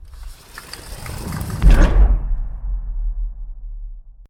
Door_Close.ogg